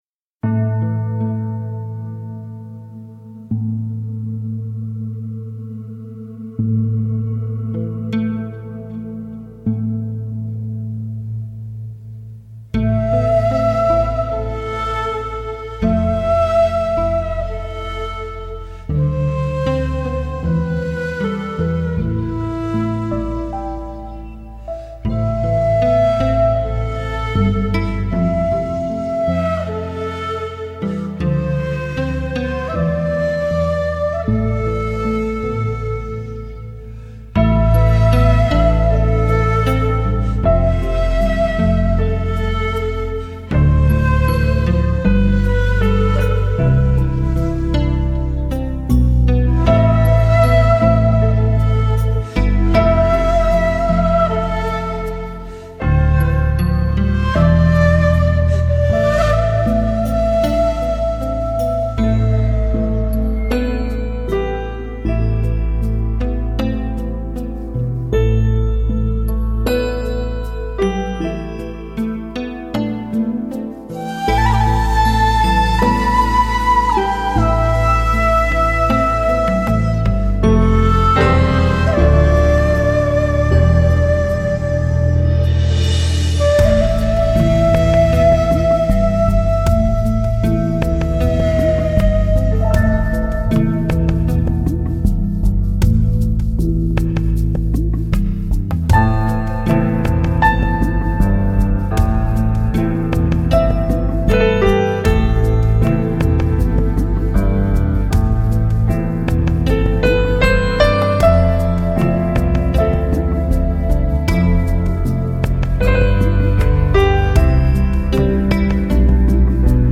尺八音色与箫近似，却比箫更富于变化，有着一种浓烈的萧杀之气。